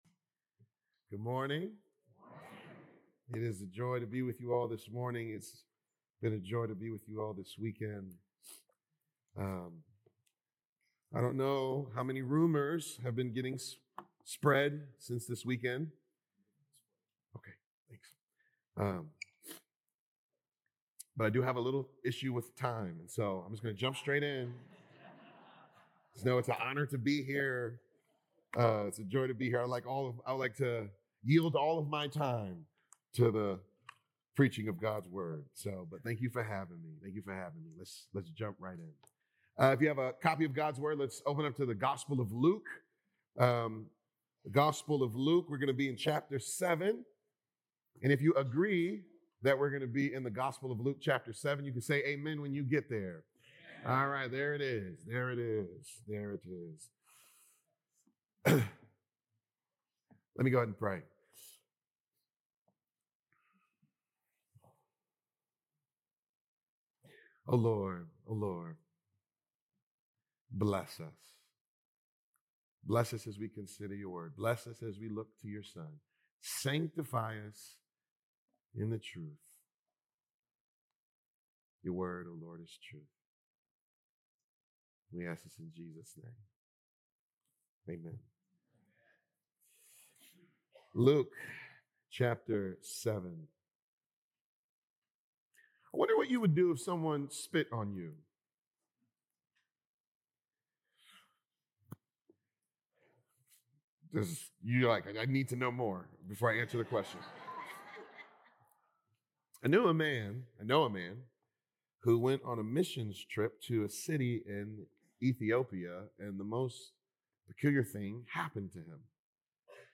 Nov 9th Sermon